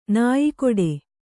♪ nāyi koḍe